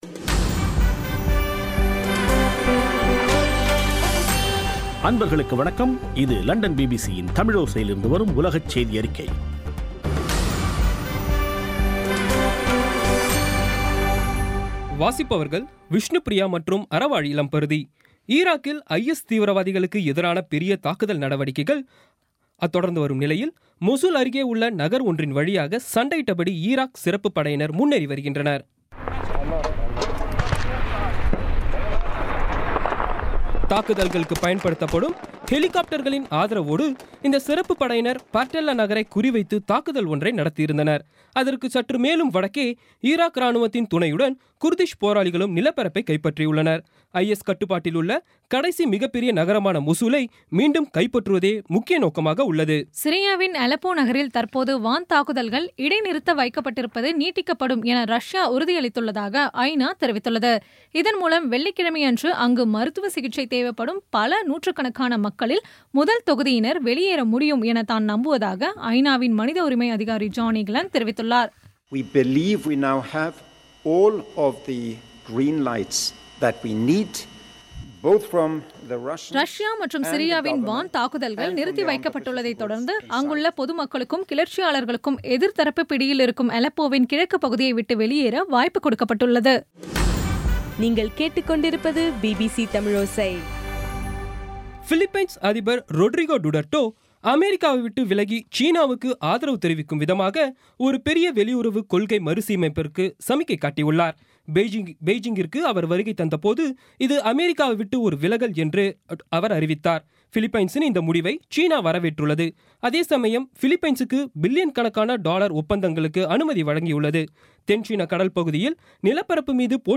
இன்றைய (அக்டோபர் 20ம் தேதி) பிபிசி தமிழோசை செய்தியறிக்கை